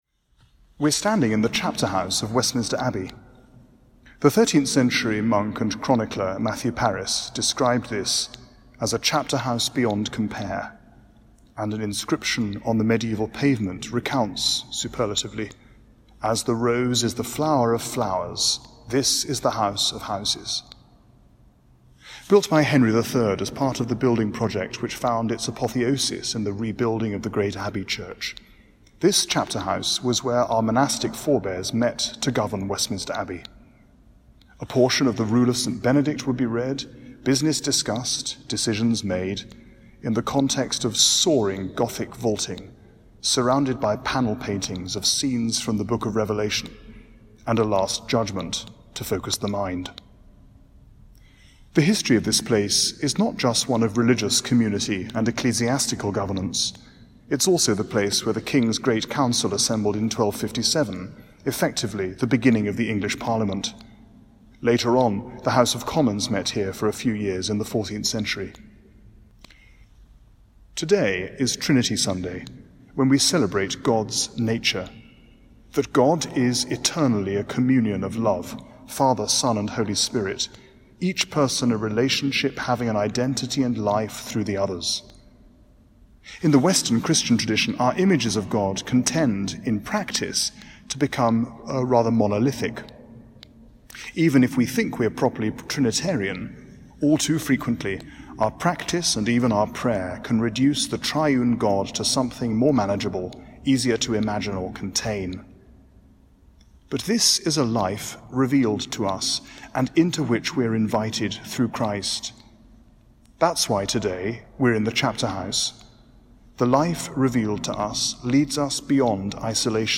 A 19-minute service of prayers, reading, address, music and blessing recorded this week in the 13th century Chapter House in the East Cloister – originally a meeting place where the monks gathered with the abbot to pray and discuss the day’s business.
Music: Performed by the Choir of Westminster Abbey
This podcast was recorded with all contributors observing social distancing.